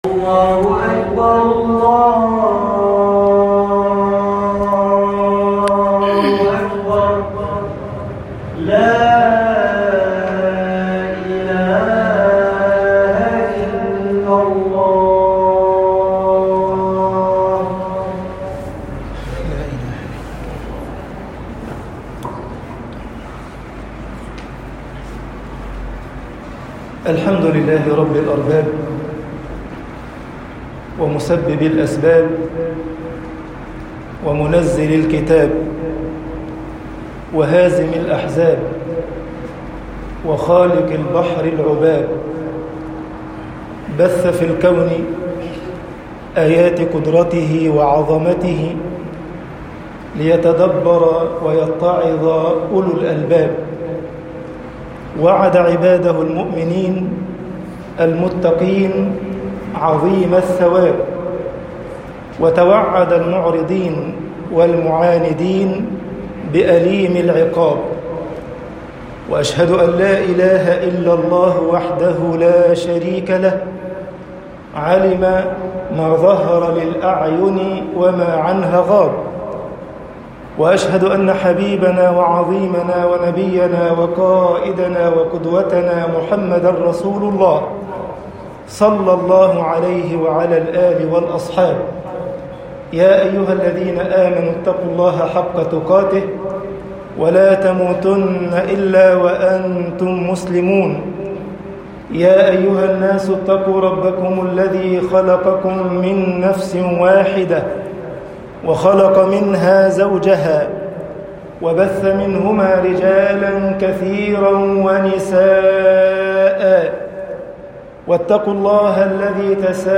خطب الجمعة - مصر حسنات يُذْهبن السَّيِّئات طباعة البريد الإلكتروني التفاصيل كتب بواسطة